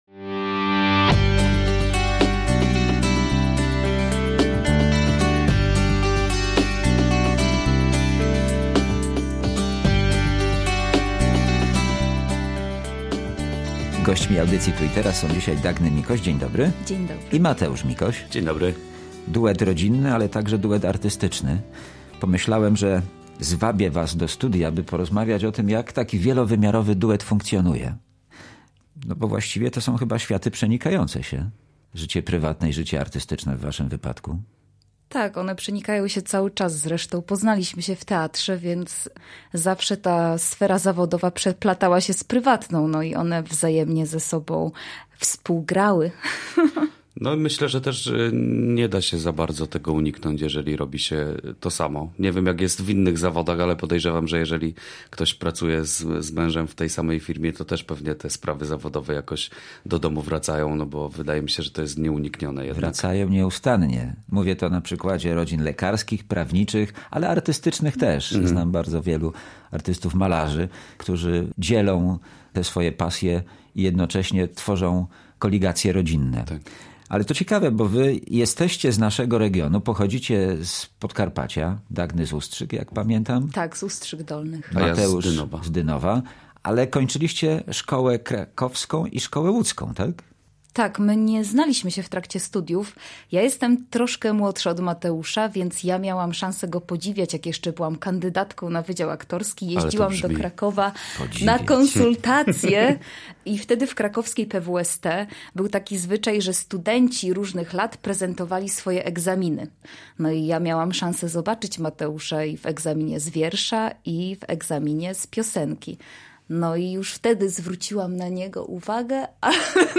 W audycji „Tu i Teraz” rozmowa o funkcjonowaniu duetu rodzinno – artystycznego.